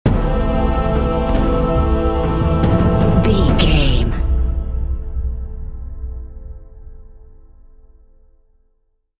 Thriller
Aeolian/Minor
SEAMLESS LOOPING?
synthesiser
drum machine
horns
percussion